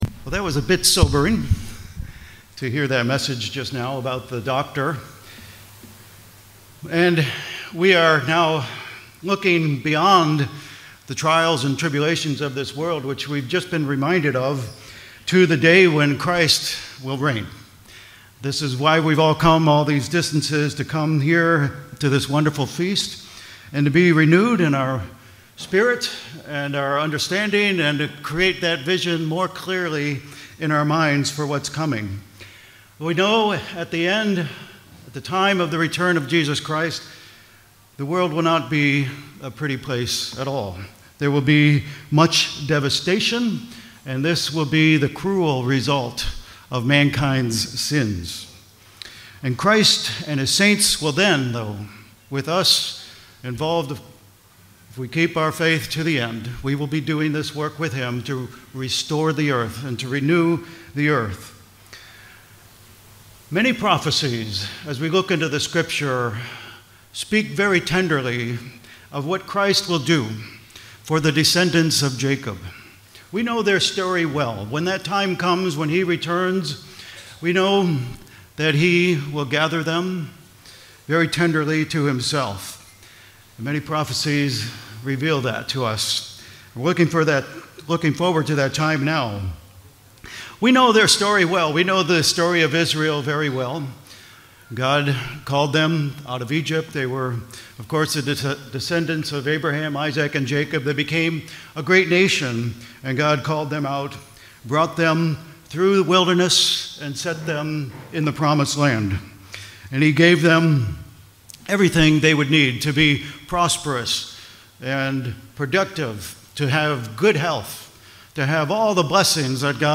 FoT 2024 Marina di Grosseto (Italy): 3rd day